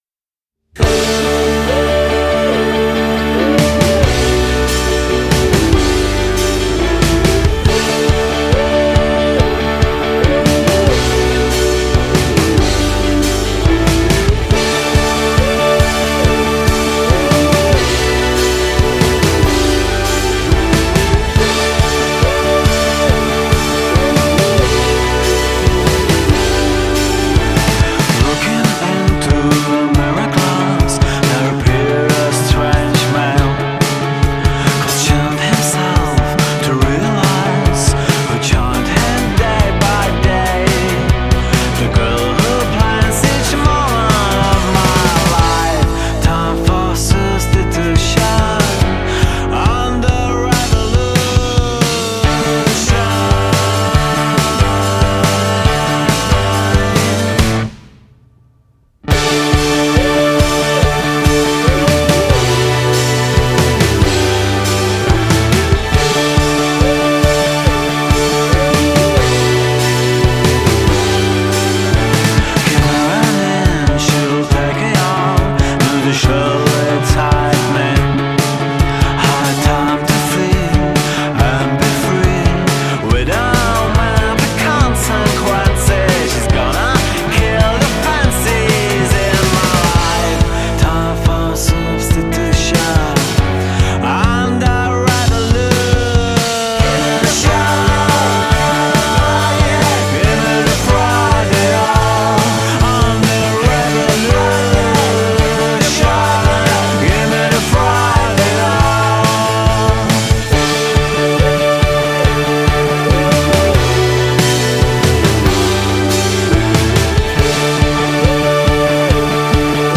Žánr: Indie/Alternativa
duchu výrazných melodických linek a ostrovního soundu.